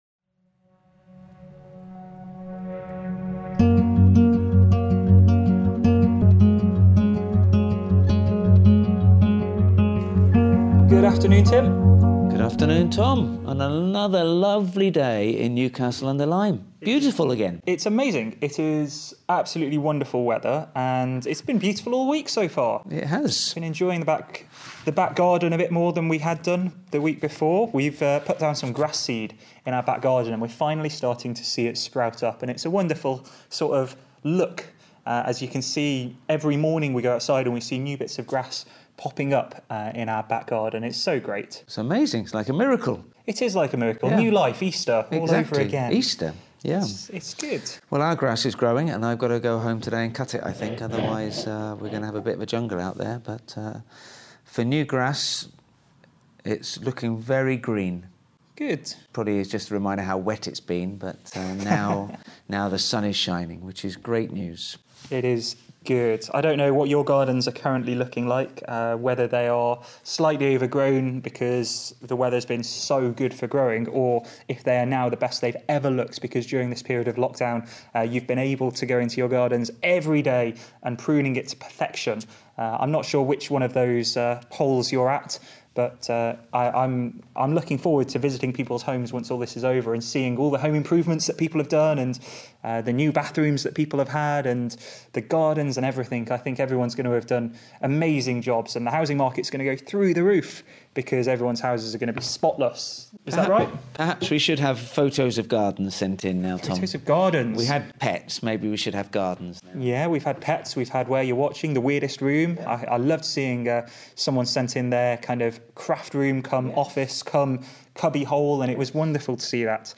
For the next six weeks we will be continuing to explore '40 Days with Jesus' in our weekly chat, focussing on some of the encounters people had with the risen Jesus.